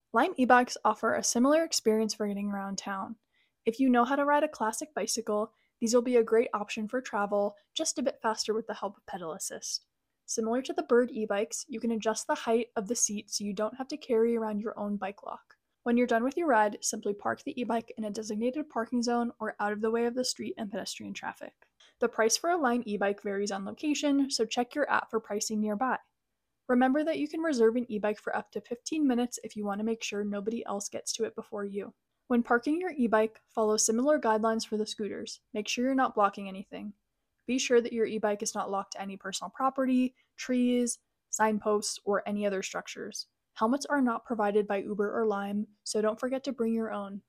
lime ebike
lime-ebike.m4a